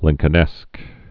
(lĭngkə-nĕsk)